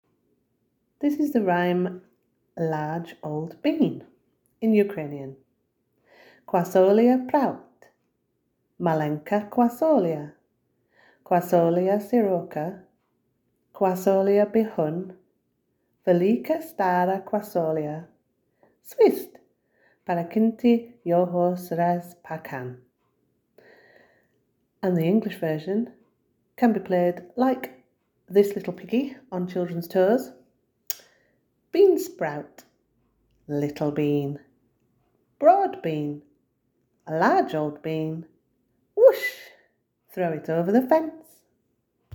large-old-bean-rhyme-in-ukrainian.m4a